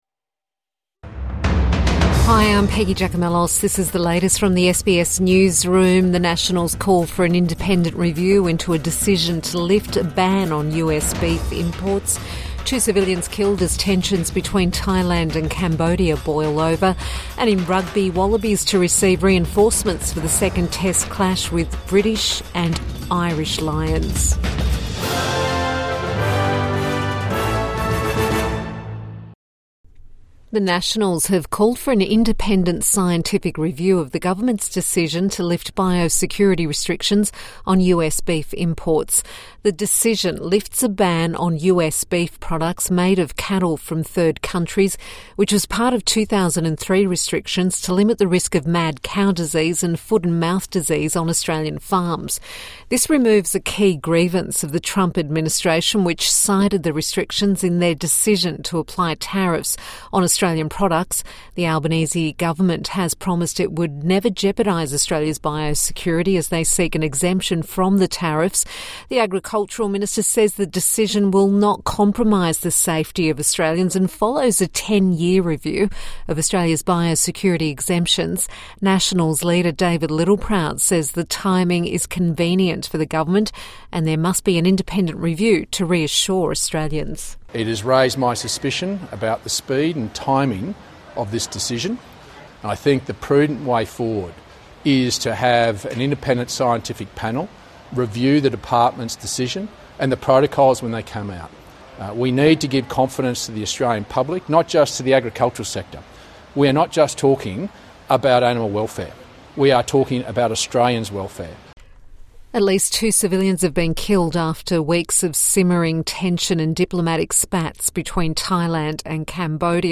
Border tensions between Thailand and Cambodia boil over | Evening News Bulletin 24 July 2025